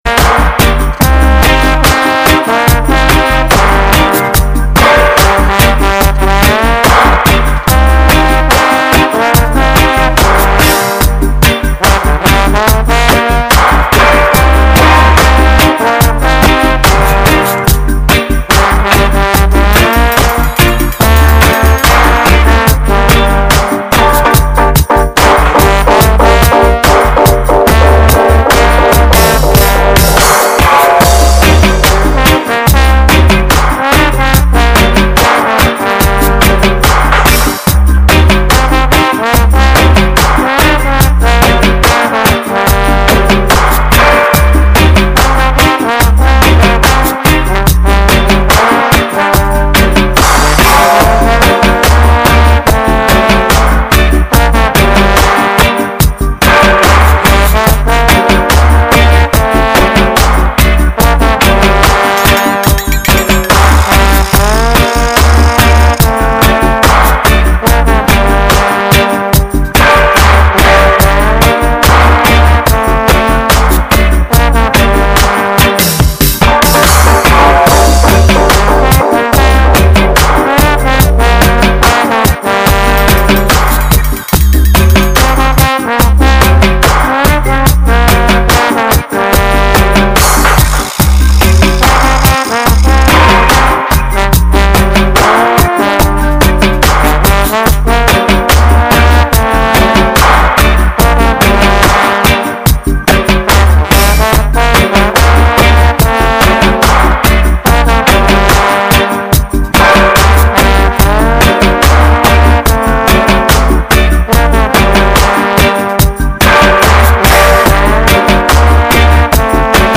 sorry for likkle technical problems in the first 10 minutes; Spirit Of Umoja "sound system culture" radio show
Promoting the good Roots'n'culture food, dinner time for your ears!!